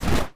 Impact3.wav